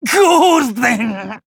坂田金时_受击1.wav